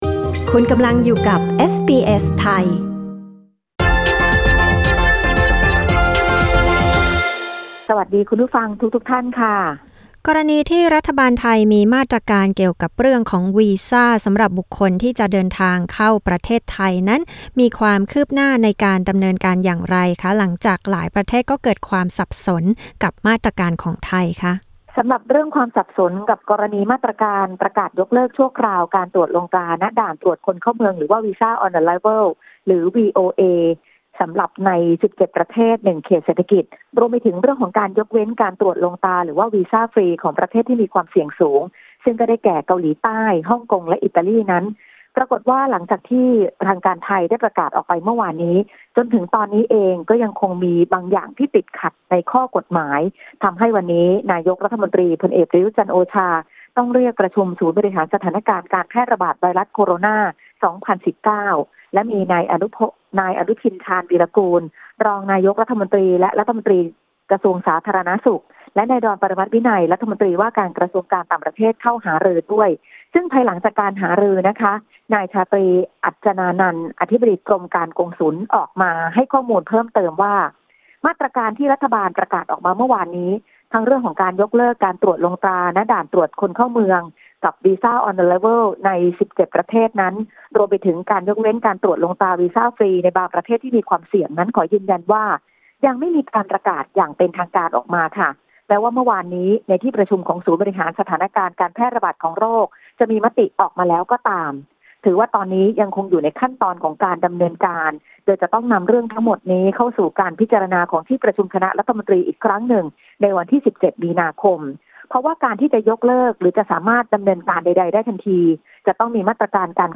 กดปุ่ม 🔊ที่ภาพด้านบนเพื่อฟังรายงานข่าว